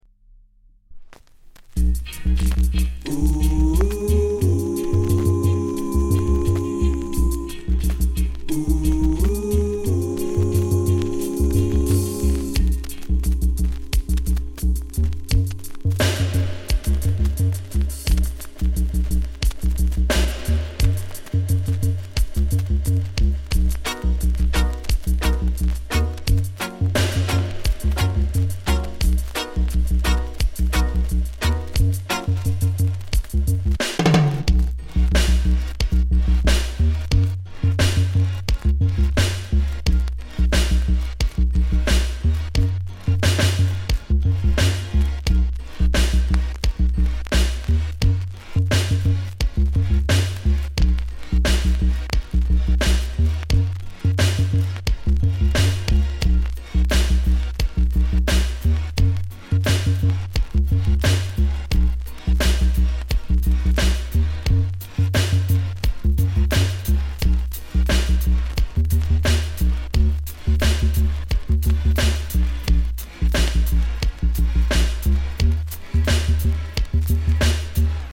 軽いチリ 乗りますが、気になるレベルではありません。
類別 雷鬼